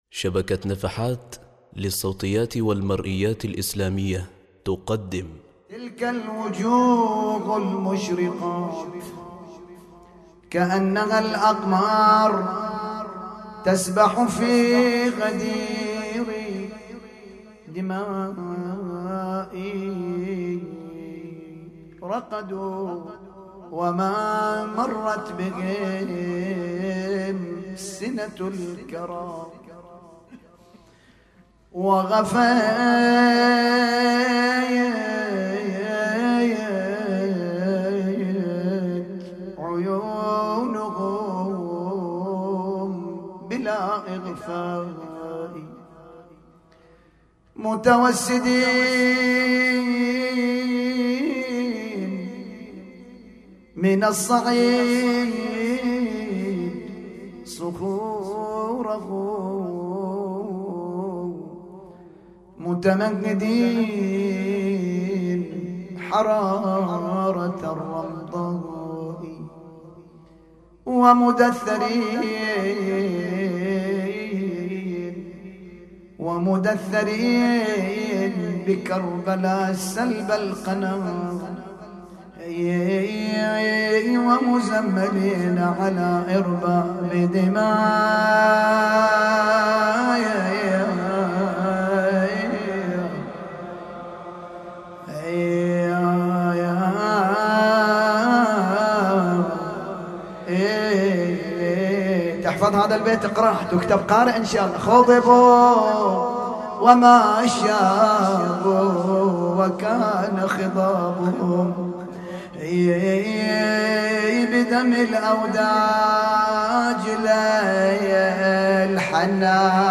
نعي ليلة 8 محرم 1439هـ |